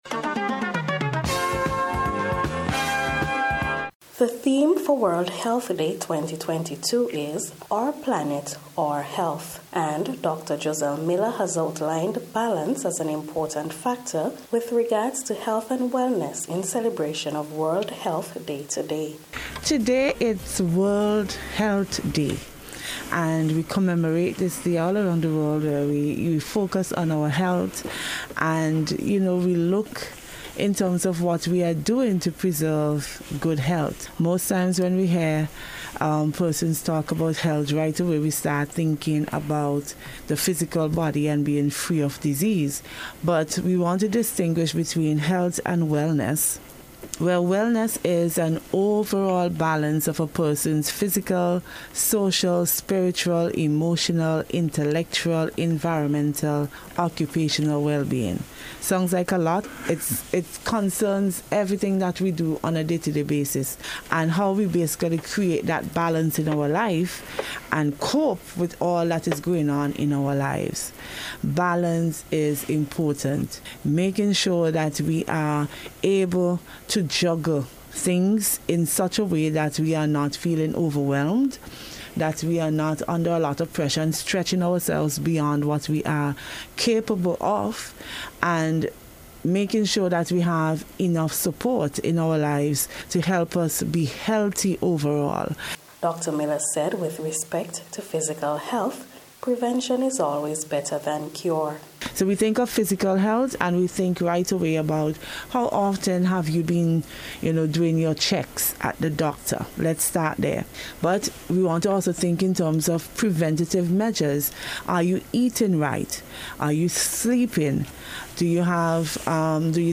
NBC’s Special Report for April 7th 2022